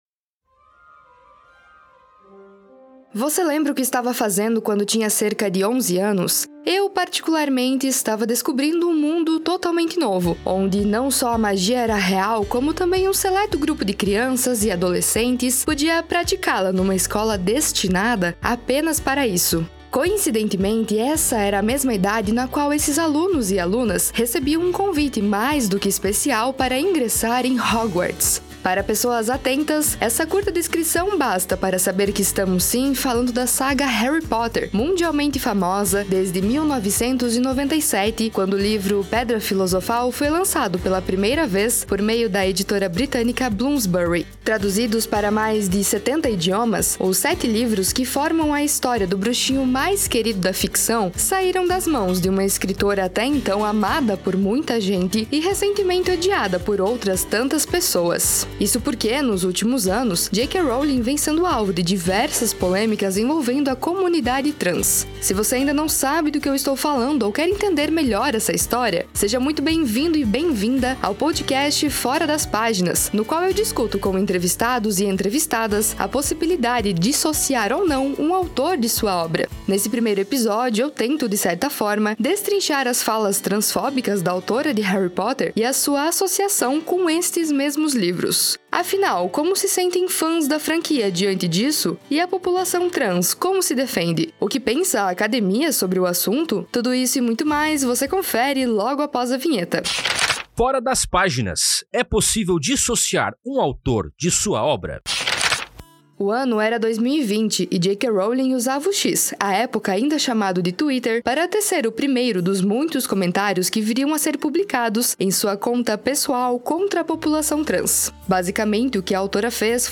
Para isso, o podcast “Fora das Páginas” buscou entrevistar não somente fontes ligadas ao assunto como também ouvir de especialistas como o tema vem repercutindo nos últimos anos no âmbito acadêmico.